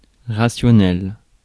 Prononciation
Synonymes logique discursif Prononciation France Accent inconnu: IPA: [ʁa.sjɔ.nɛl] Le mot recherché trouvé avec ces langues de source: français Traduction 1.